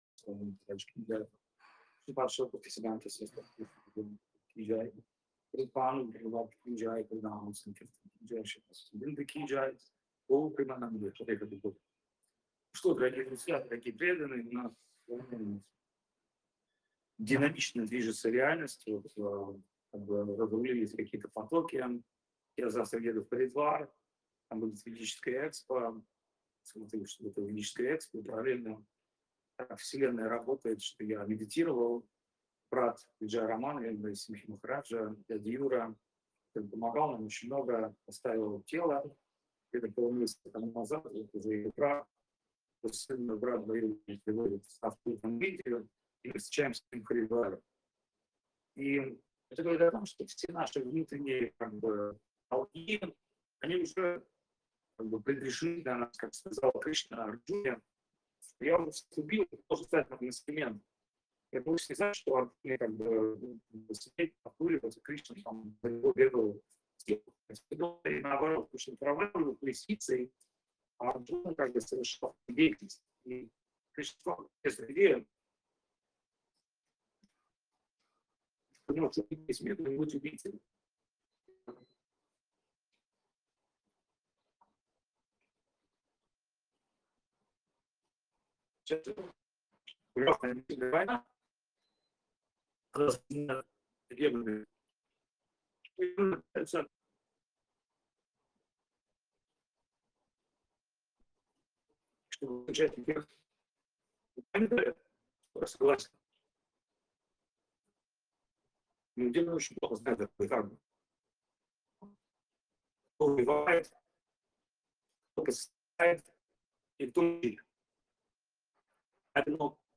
Вриндаван Дхама, Индия
Лекции полностью